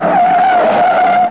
SKID6.mp3